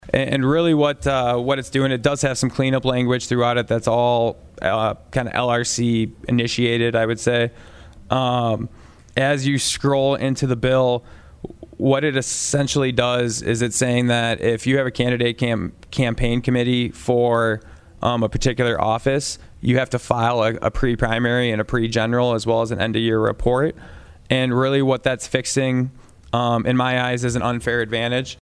PIERRE, S.D.(HubCityRadio)- District 1 Senator Michael Rohl presented SB17 to the South Dakota Senate State Affairs Committee on Wednesday.
Senator Rohl explains the purpose of the bill.